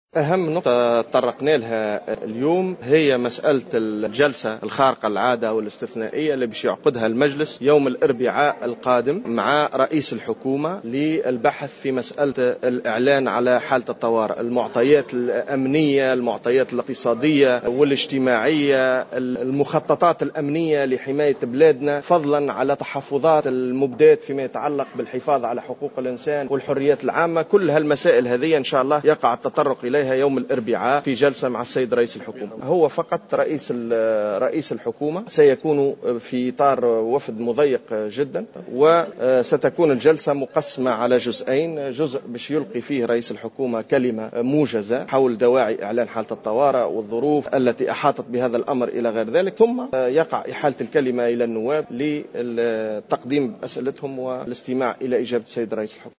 و أكد خالد شوكات الناطق الرسمي باسم المجلس في تصريح لمراسل جوهرة "اف ام" أن جلسة الحوار مع الصيد ستخصص لتدارس تداعيات إعلان حالة الطوارئ، من جوانبها الأمنية والاقتصادية، ومدى تأثيرها على الحريات العامة وحقوق الإنسان في البلاد.